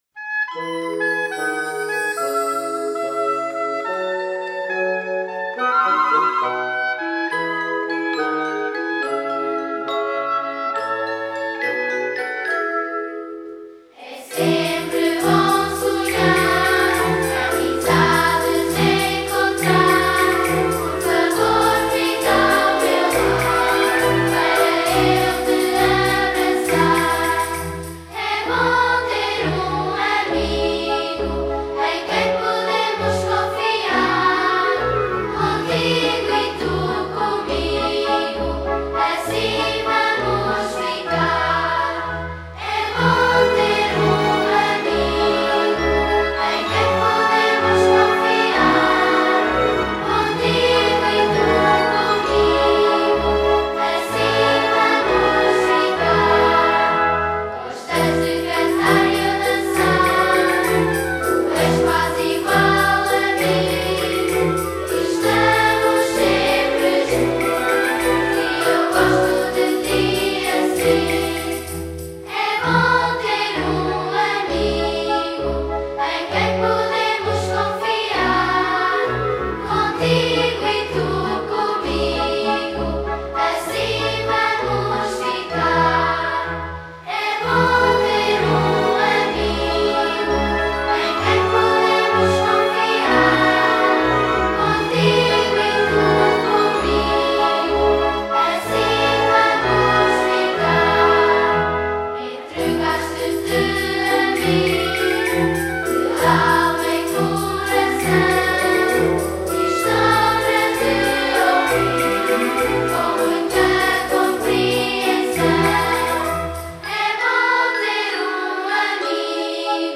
com voz